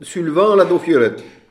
Saint-Hilaire-des-Loges
Catégorie Locution